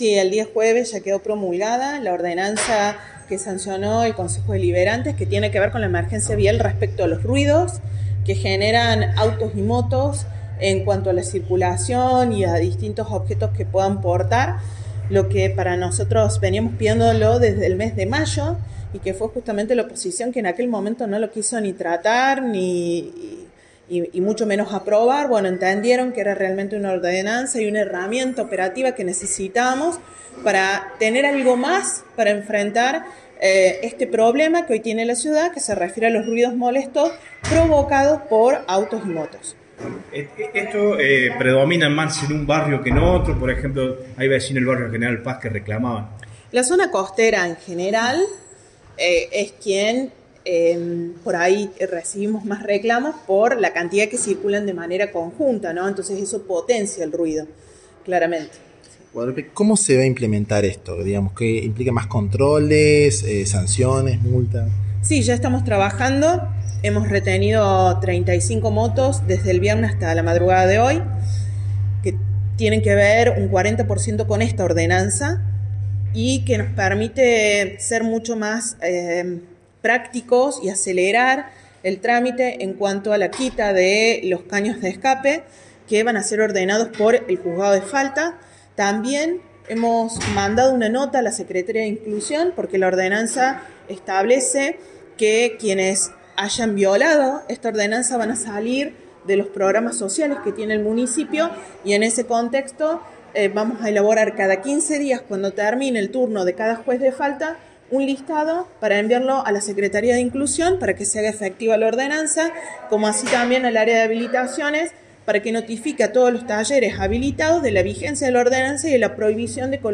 «El legislador local entendió que si hay recursos para alterar un vehículo y molestar al resto, tiene recursos para todo lo otro que hace a la vida cotidiana», dijo la secretaria de Seguridad del municipio, Guadalupe Vázquez, en rueda de prensa.